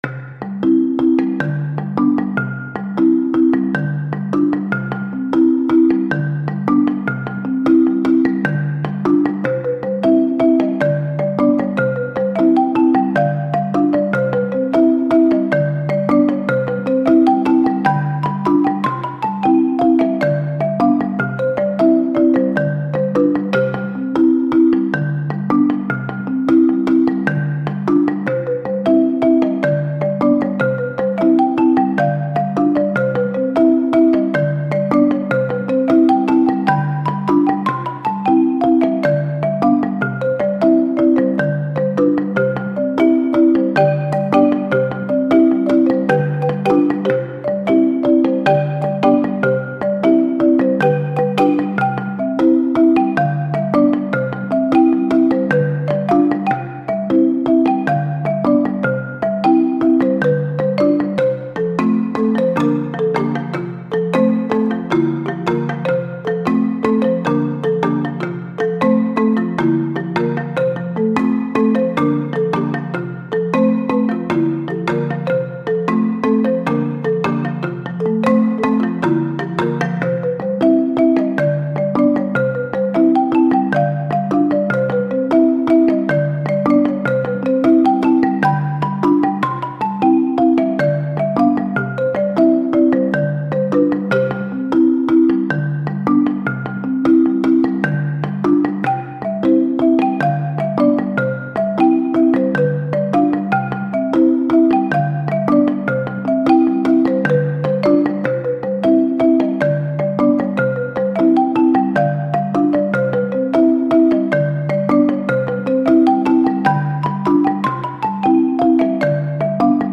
P3 Marimba
DUET
intermediate marimba duet
4 mallet technique is required.